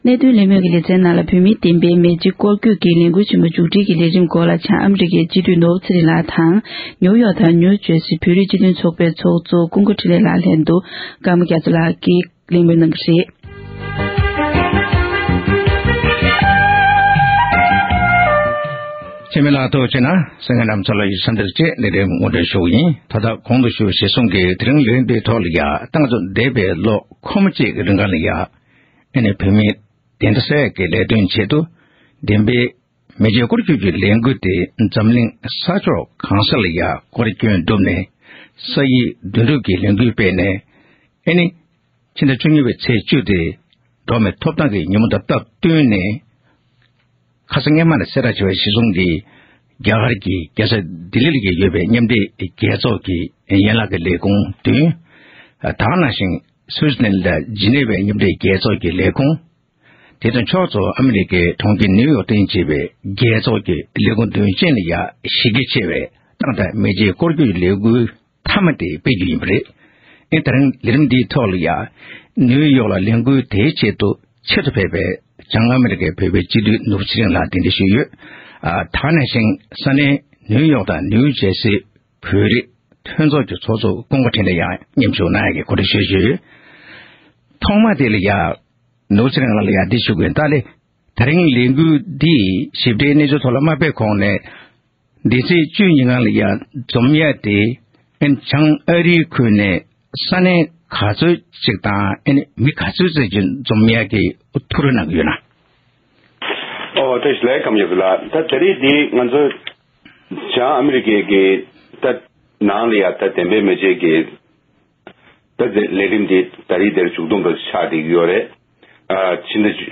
༄༅༎ཐེངས་འདིའི་གནད་དོན་གླེང་མོལ་གྱི་ལེ་ཚན་ནང་།